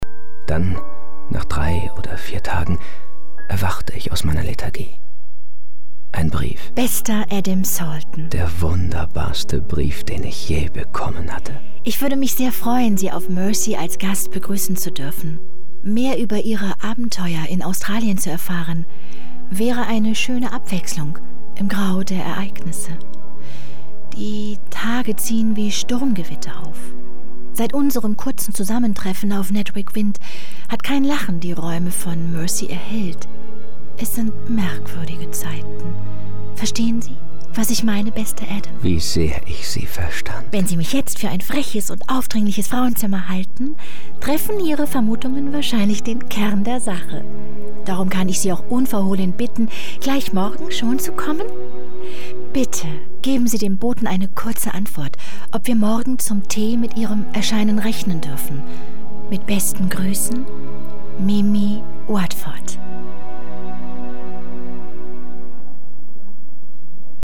hell, fein, zart, sehr variabel
Audio Drama (Hörspiel)